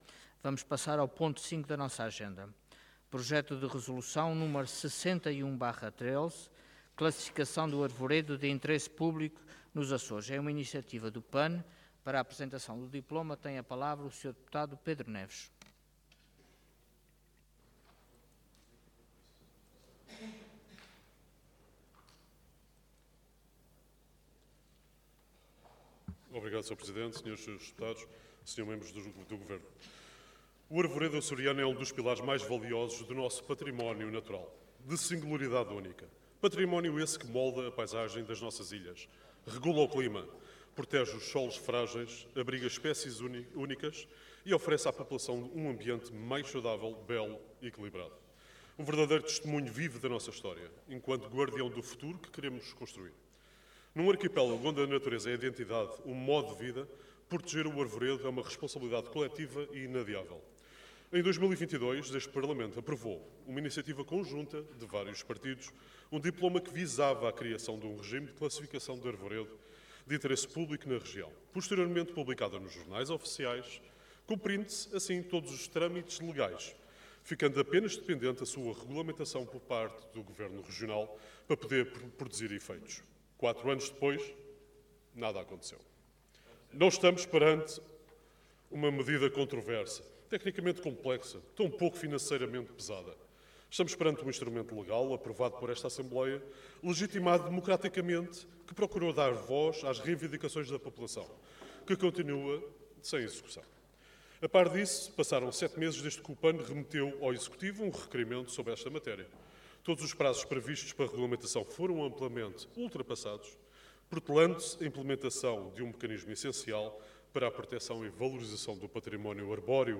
Intervenção
Orador Pedro Neves Cargo Deputado